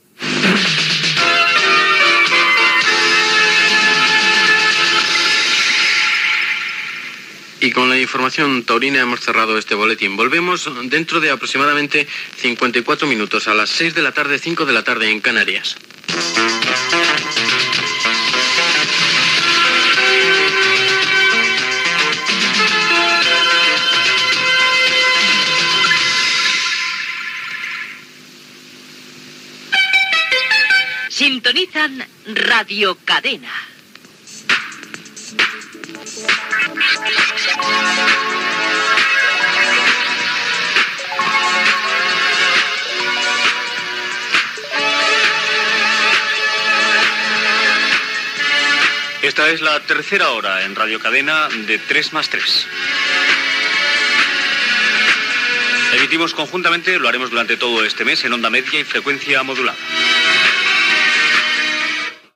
Final de l'informatiu, indicatiu de la ràdio, presentació de la tercera hora del programa
Entreteniment